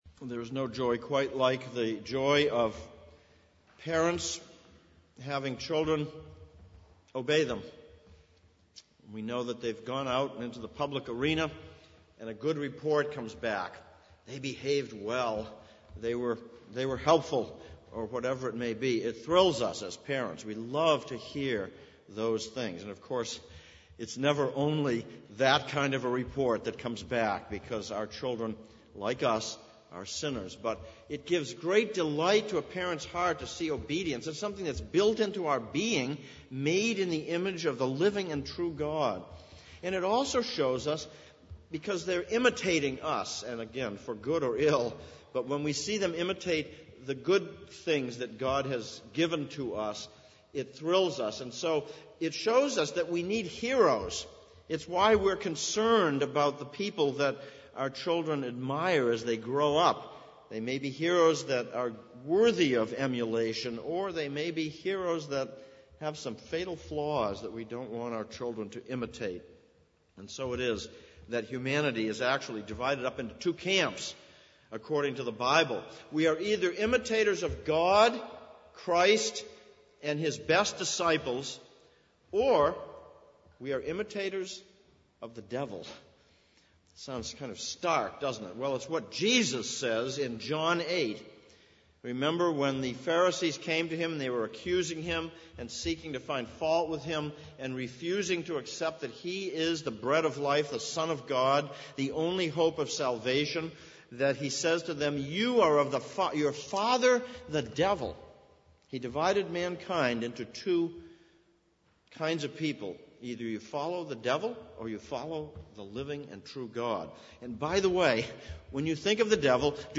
Ephesians 2011 Passage: Ephesians 5:1-14, Psalm 62:1-12 Service Type: Sunday Morning « Week 2.